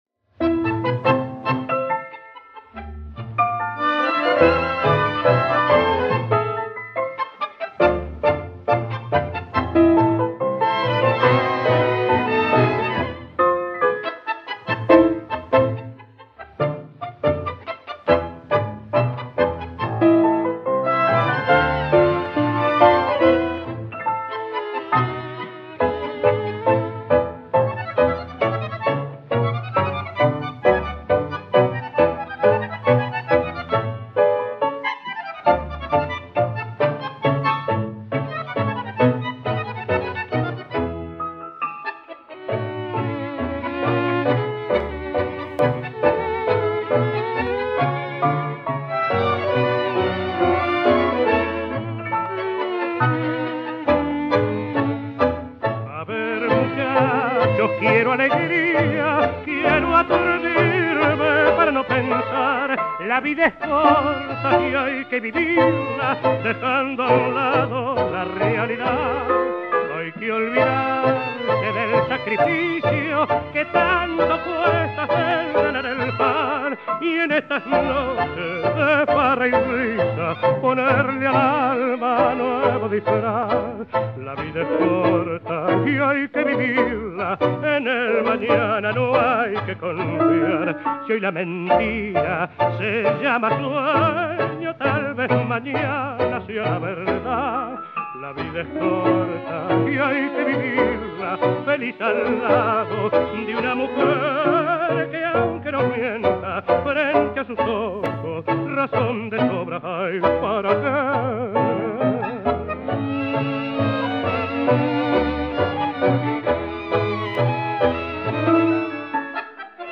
Why do so many songs have joyful sound, and such dark words?